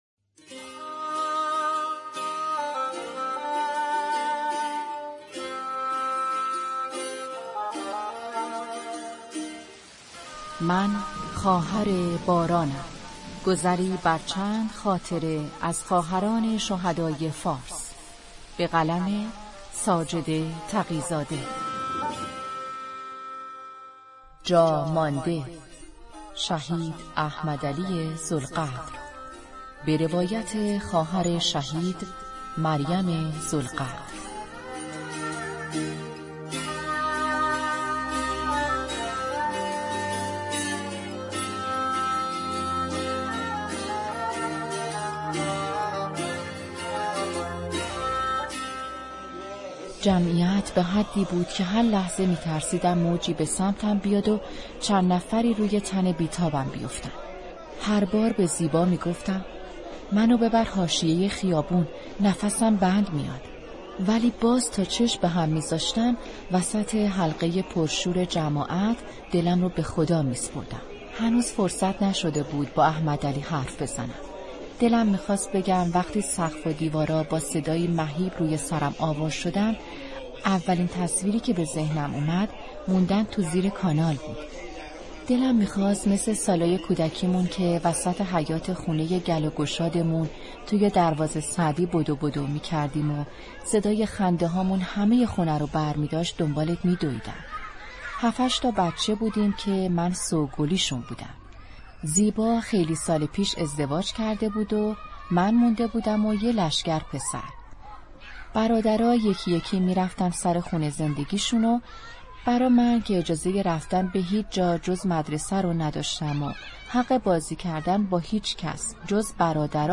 کتاب صوتی «من خواهر بارانم» بخش نهم